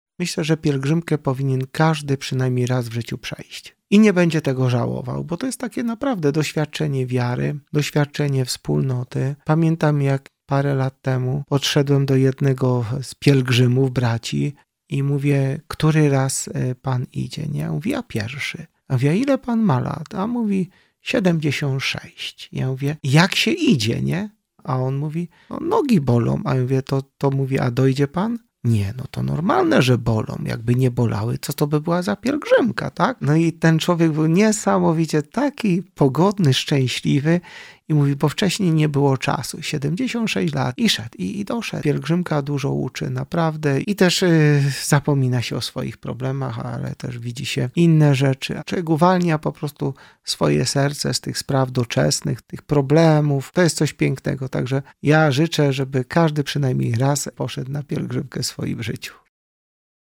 Biskup pomocniczy Archidiecezji Wrocławskiej zaprasza wiernych do udziału w 45. Pieszej Pielgrzymce Wrocławskiej na Jasną Górę.